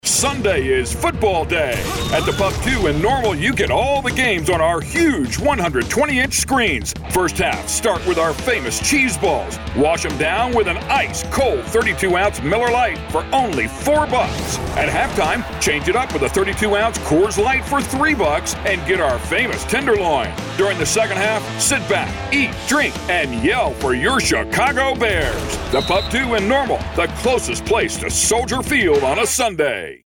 ad samples.
Dining-Commercial.mp3